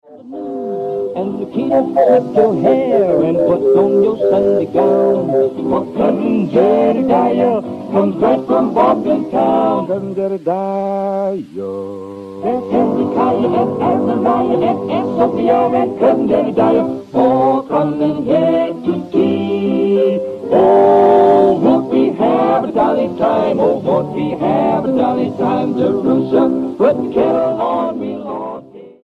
STYLE: Gospel